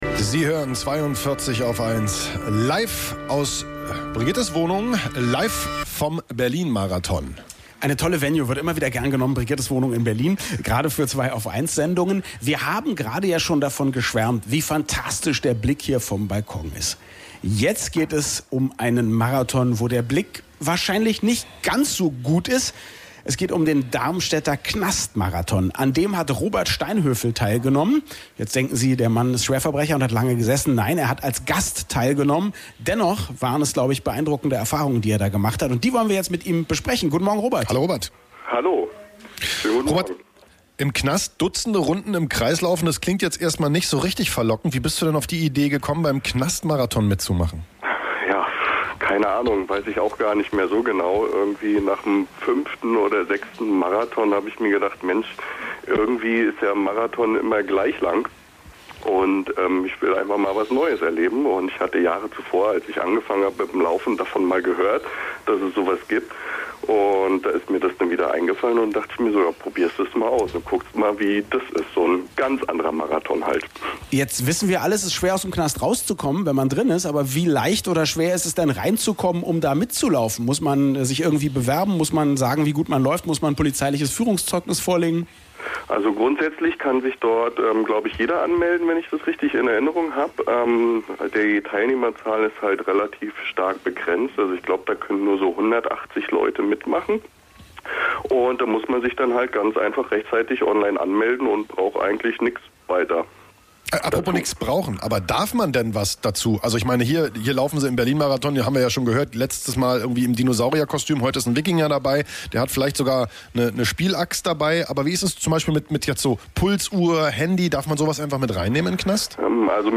Das Interview in voller Länge